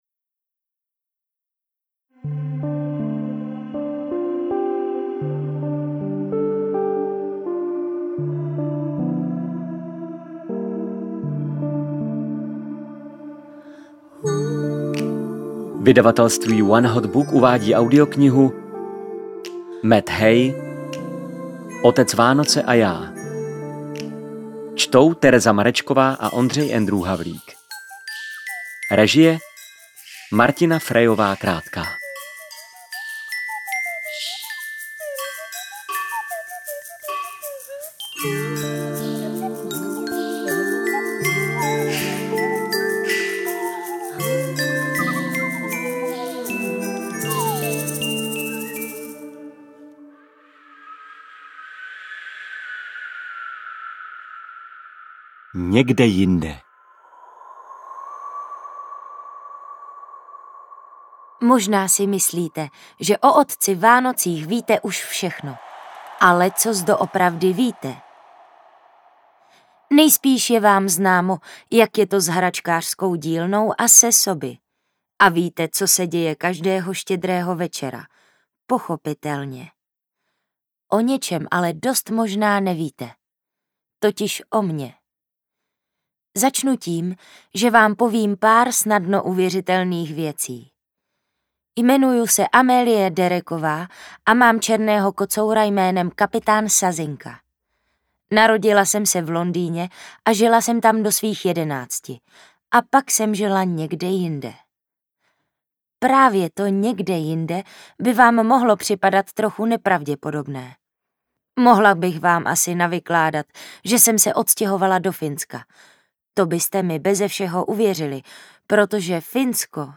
AudioKniha ke stažení, 27 x mp3, délka 6 hod. 13 min., velikost 503,8 MB, česky